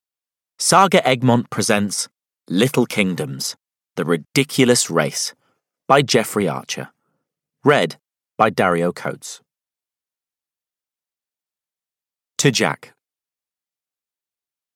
Little Kingdoms: The Ridiculous Race (EN) audiokniha
Ukázka z knihy